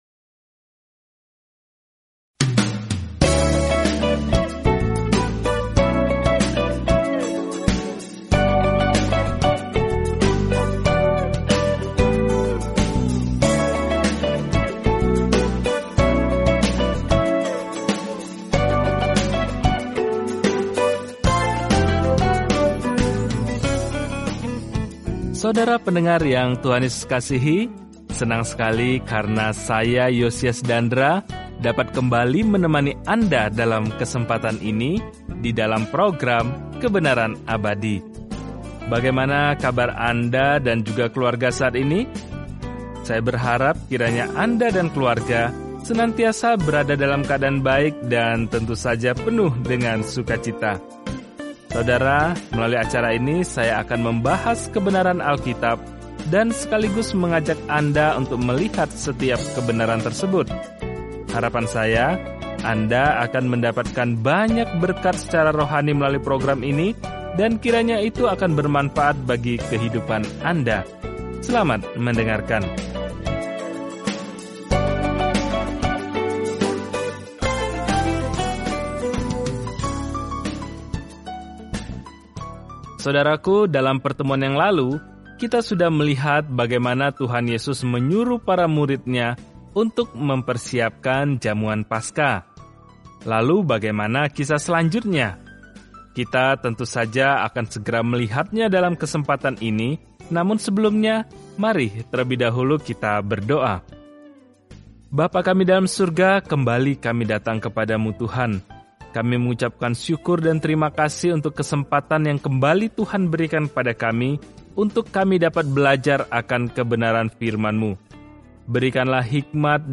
Firman Tuhan, Alkitab Markus 14:22-72 Hari 16 Mulai Rencana ini Hari 18 Tentang Rencana ini Injil Markus yang lebih singkat menggambarkan pelayanan Yesus Kristus di bumi sebagai Hamba dan Anak Manusia yang menderita. Jelajahi Markus setiap hari sambil mendengarkan studi audio dan membaca ayat-ayat tertentu dari firman Tuhan.